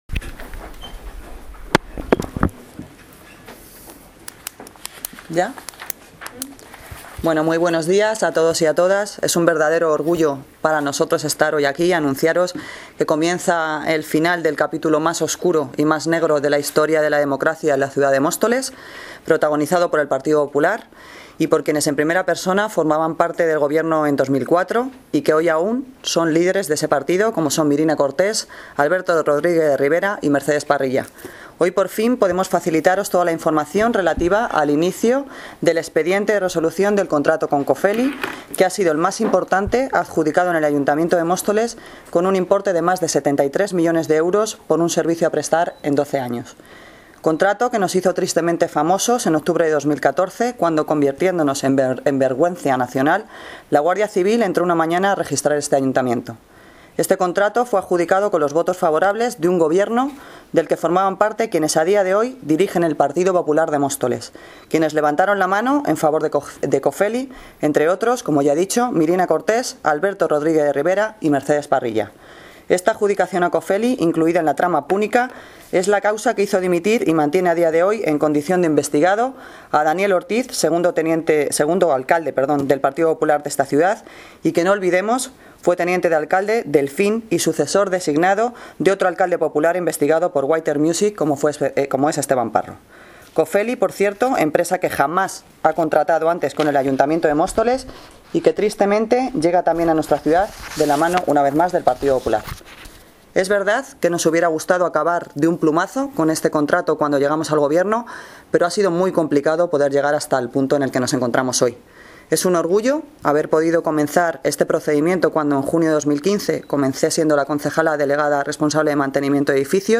Audio - Noelia Posse (Alcaldesa de Móstoles) Sobre Cofely